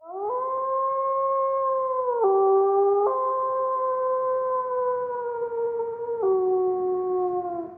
FNF_WW_foley_creature_wolf_howl_reverb